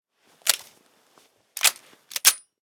BAR-reload.ogg